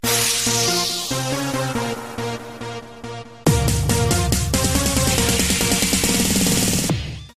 короткие
Прикольная мелодия на смс